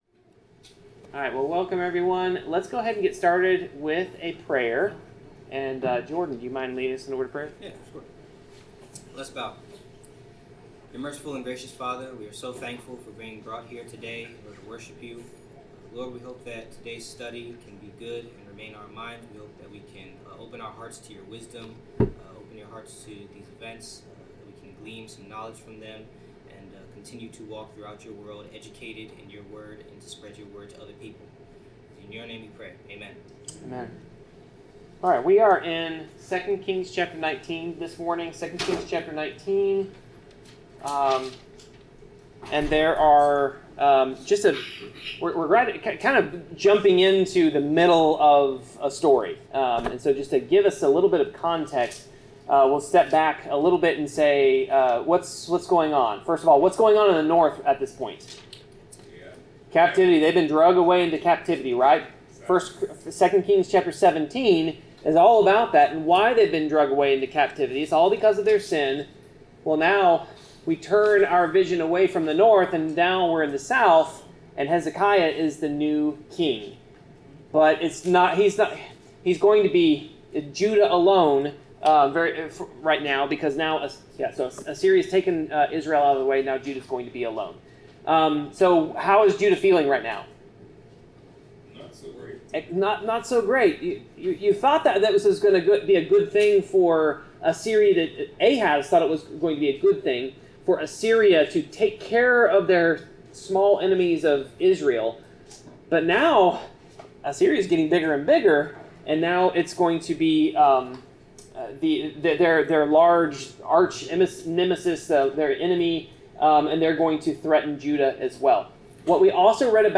Bible class: 2 Kings 19-20
Service Type: Bible Class